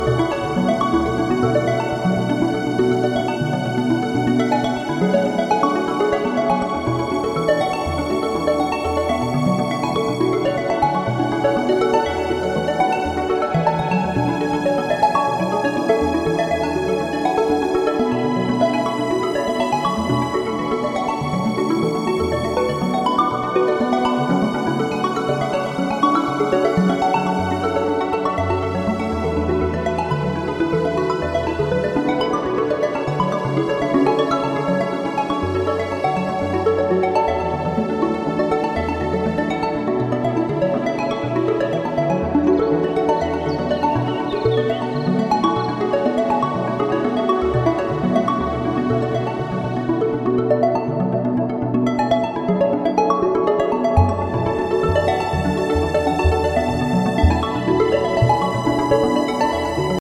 壮大なサウンドスケープは、忙しない日常の心の支えとなるメディテーションに没入できます。